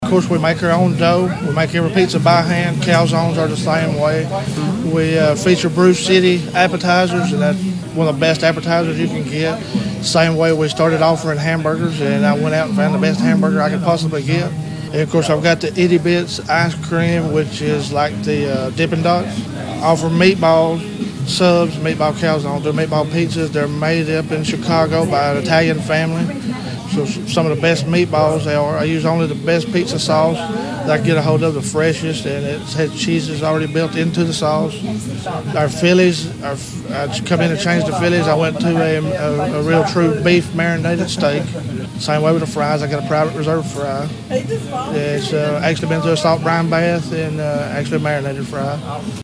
The WEIS Radio Road Crew was on hand for a live remote.